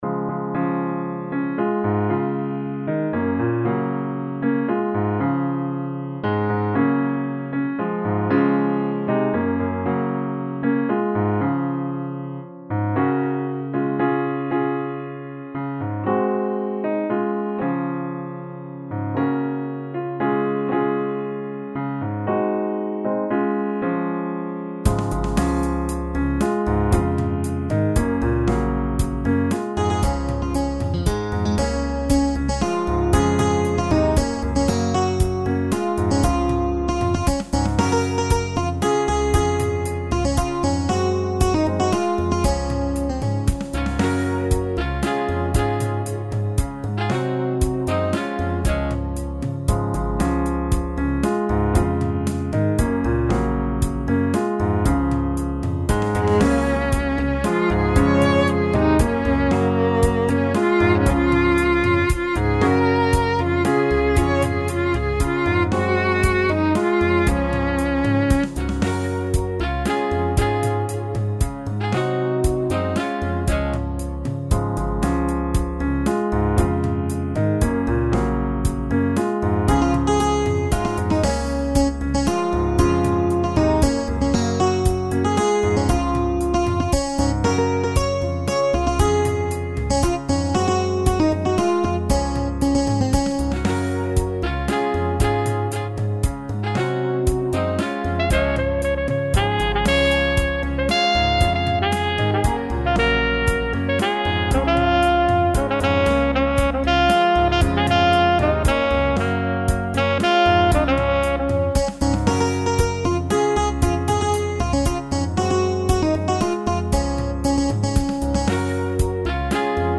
Popular South African folk tune in Xhosa tradition
piano style.
Afro-Folk (South Africa)